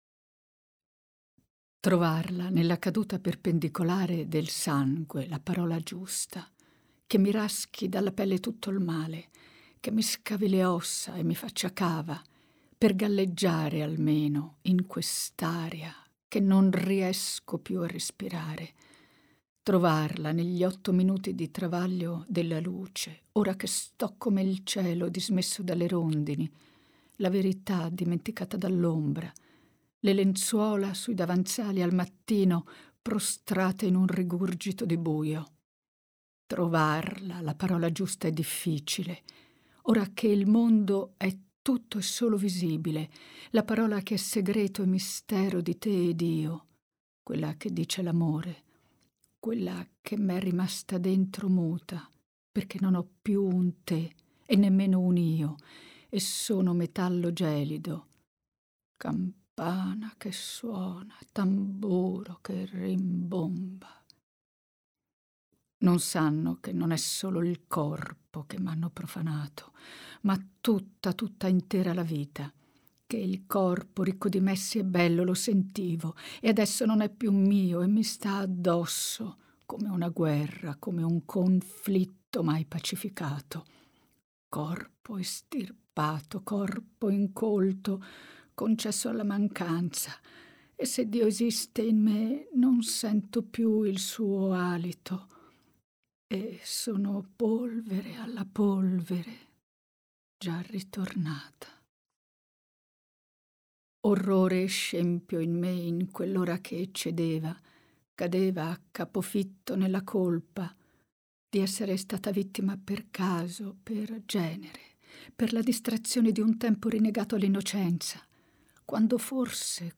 Gestazione dell’addio Voce recitante